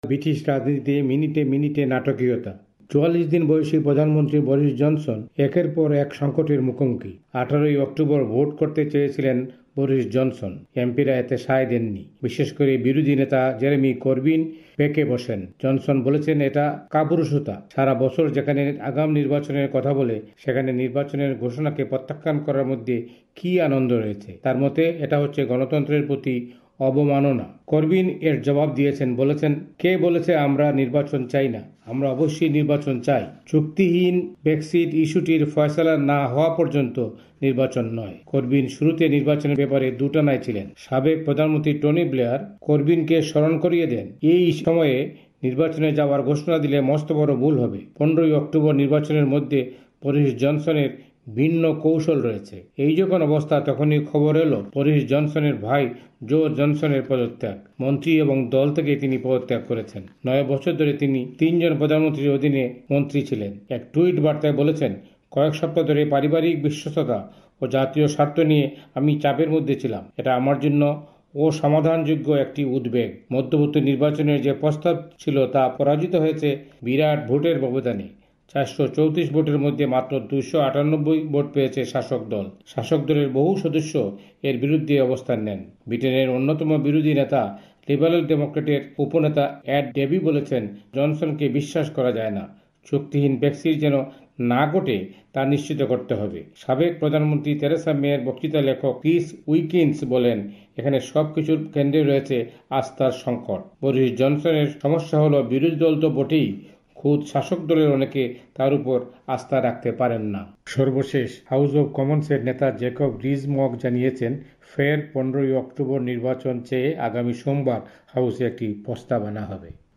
লন্ডন থেকে